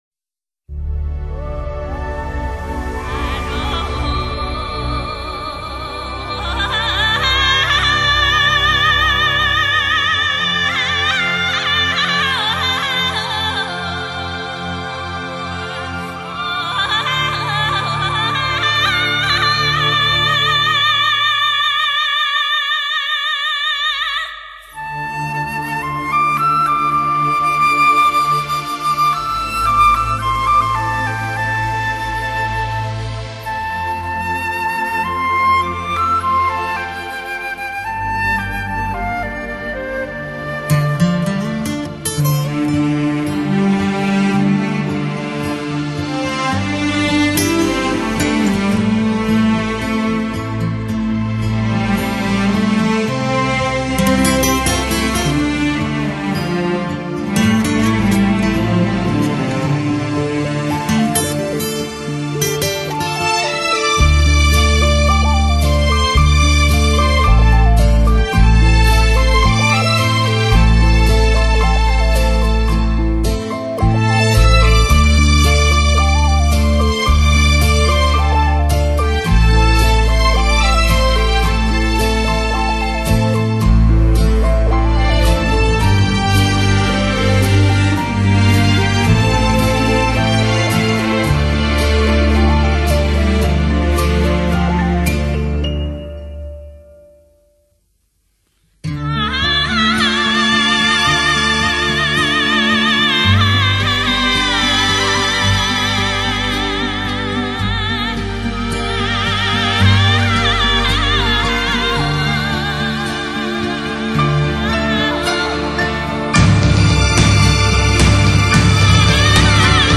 "管弦乐队+流行乐队+特色乐器"